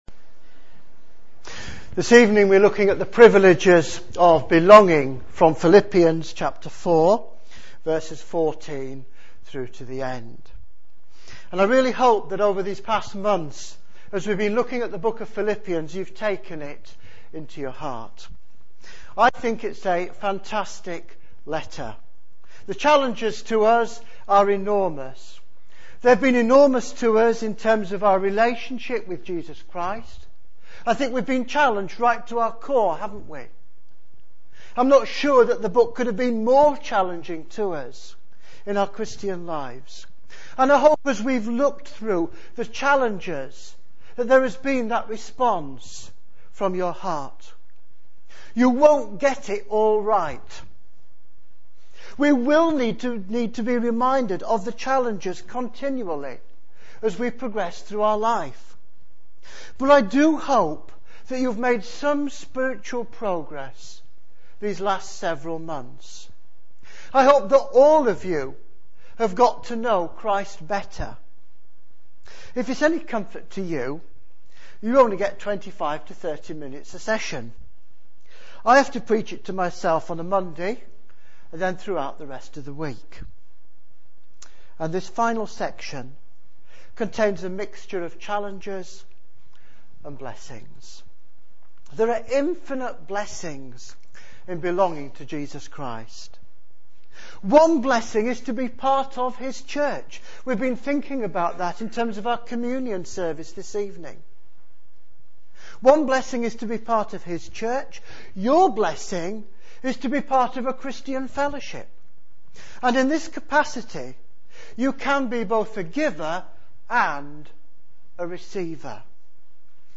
Latest Episode Philippians 4:14-23 The privileges of belonging Download the latest episode Note: in some browsers you may have to wait for the whole file to download before autoplay will launch. A series of sermons on Paul's letter to the Philippians.